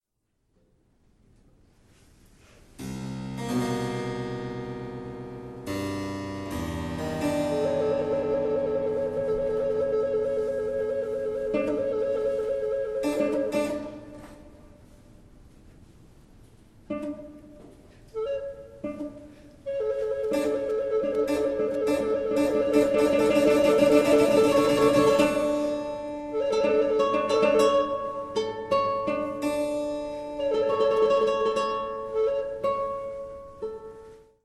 Für Blockflöte, Gitarre und Cembalo
Neue Musik
Modern/Avantgarde
Trio
Blockflöte (1), Gitarre (1), Cembalo (1)